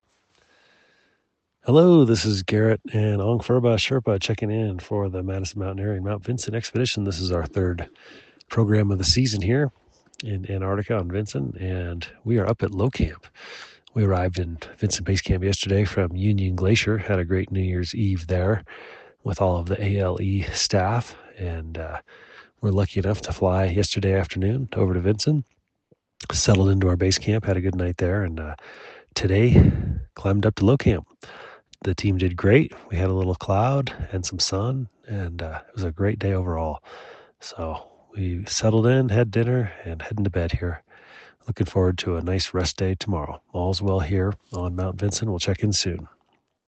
checks in with this dispatch from Mount Vinson: